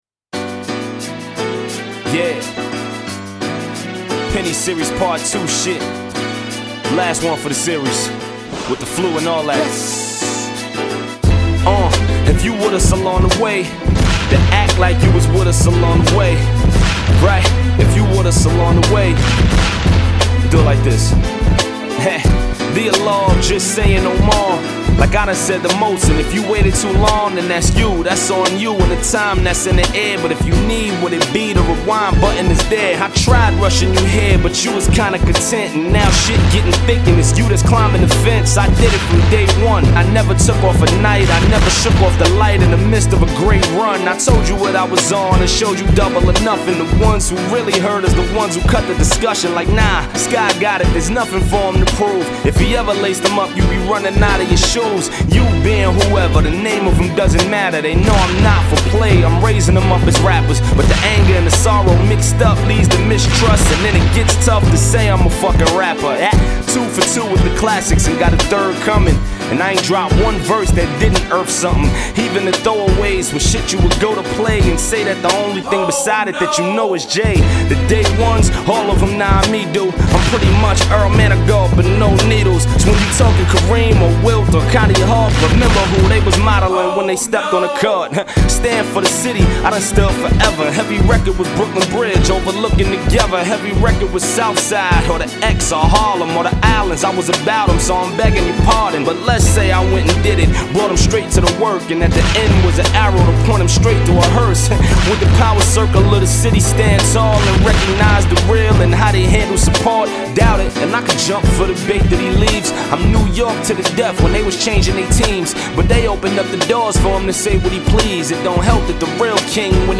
a signature beat